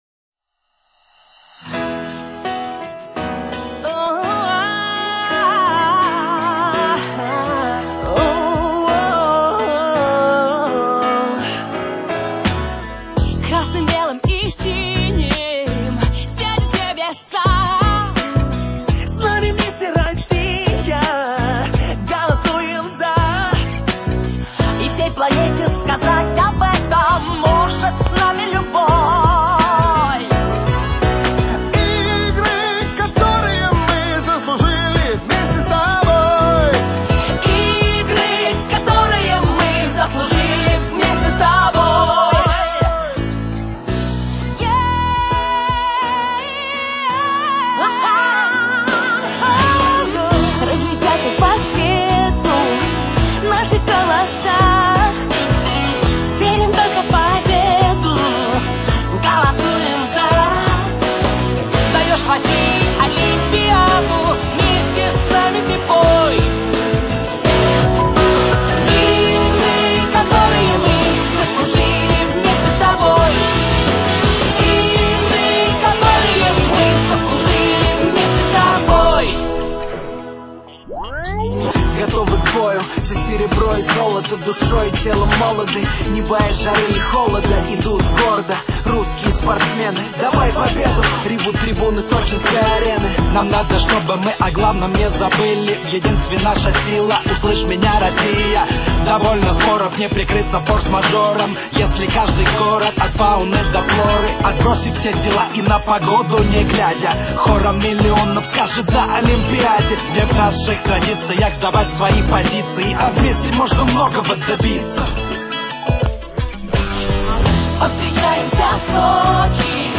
за СОЧИ 2014 ВСЕ и у него есть свой гимн!!! smile smile
Гимн на мой взгляд не очень получился, уж слишком сильно он мне что то знакомое напоминает, чуство будто плагиат!!! sad sad